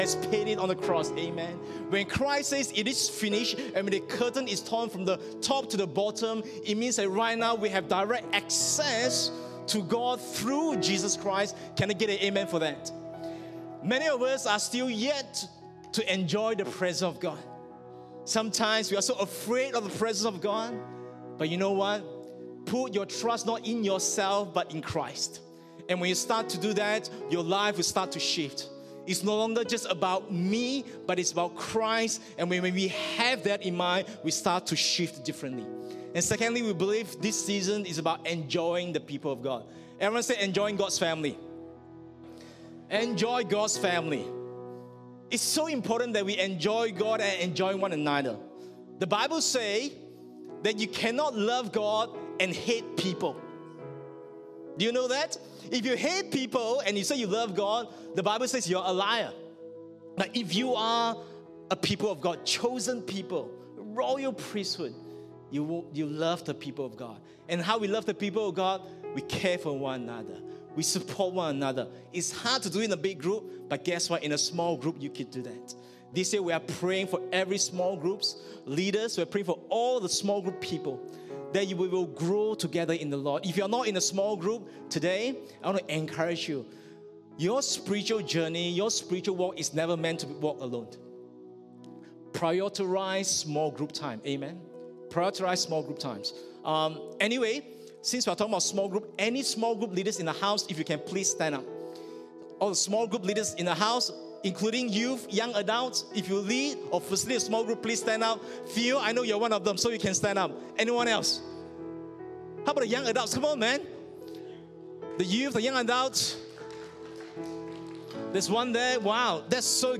English Sermons | Casey Life International Church (CLIC)